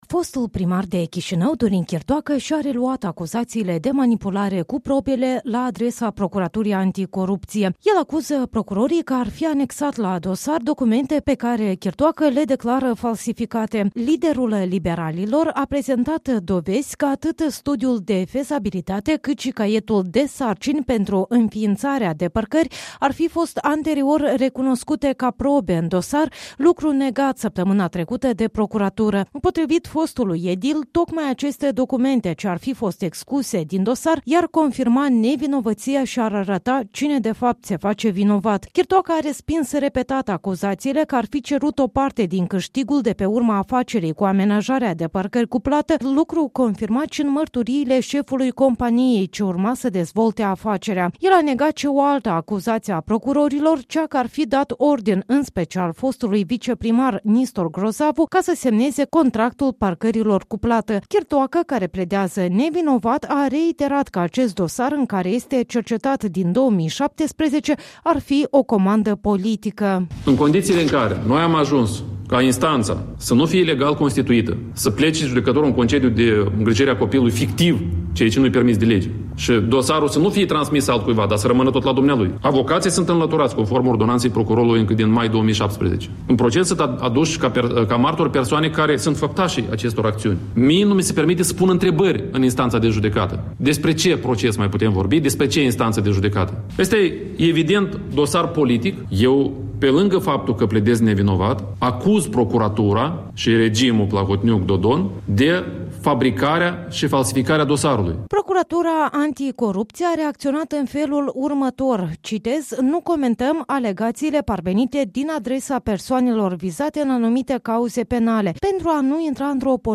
Chirtoacă, care pledează nevinovat, a declarat luni, 15 aprilie, la o conferinţă de presă că unele probe din dosar ar fi fost ascunse.